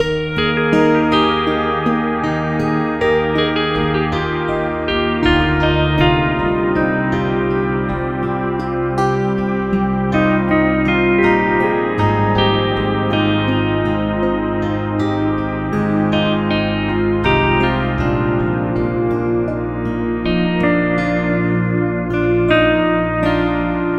流行吉他80Bpm
描述：这里有一些新的更忧郁的循环给你... 希望你喜欢它 :)
Tag: 80 bpm Pop Loops Guitar Electric Loops 4.04 MB wav Key : Unknown